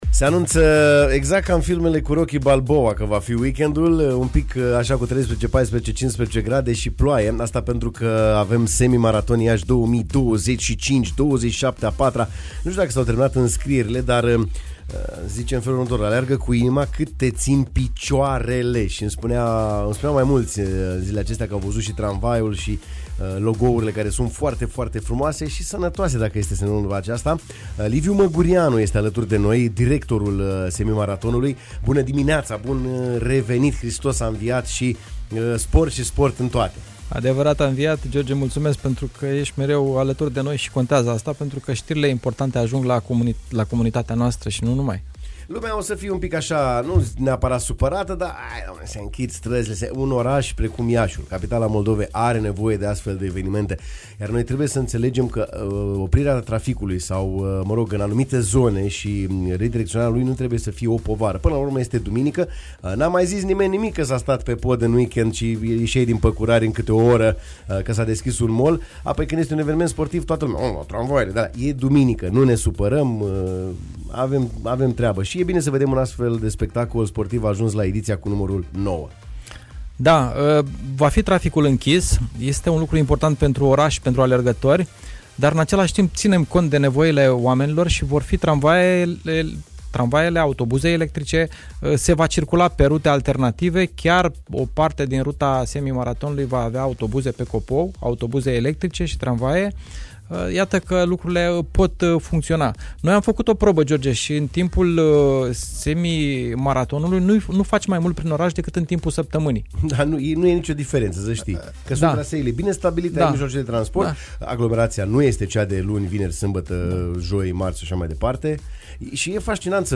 în direct